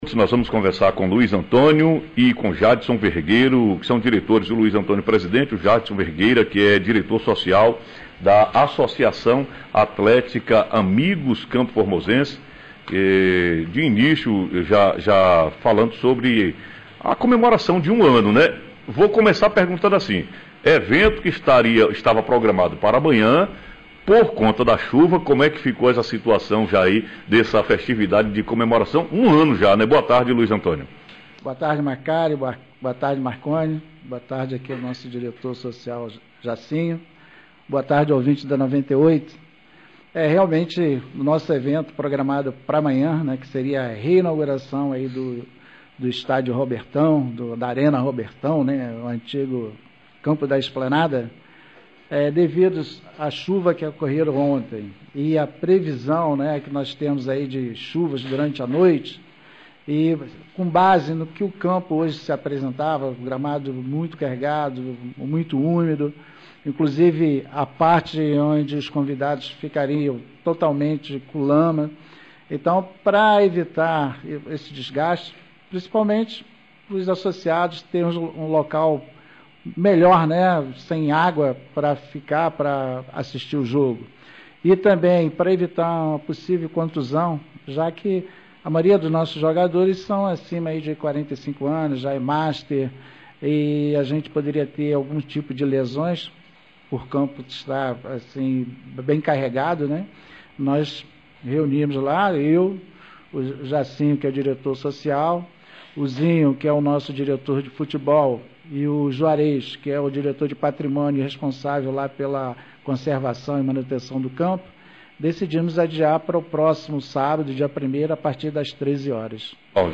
Entrevista- Membros da Associação atlética dos amigos campoformosenses